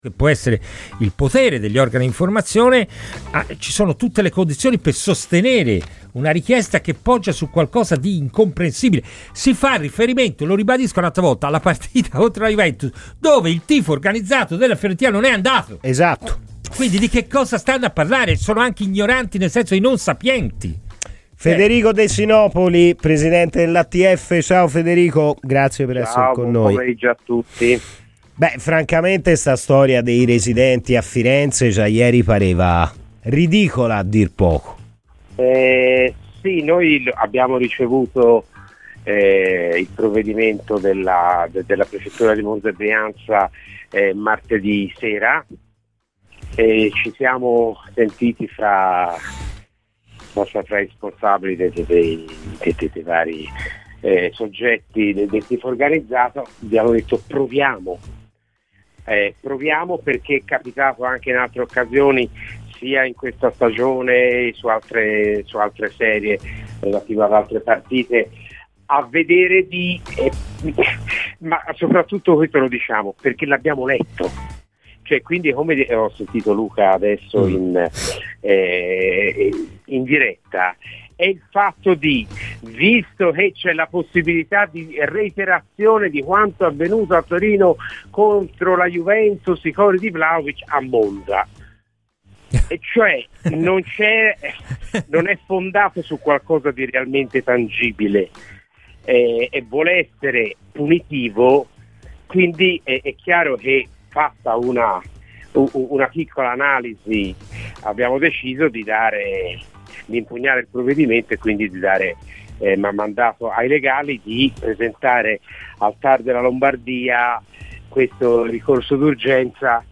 è intervenuto ai microfoni di Radio FirenzeViola nella trasmissione "Palla al Centro"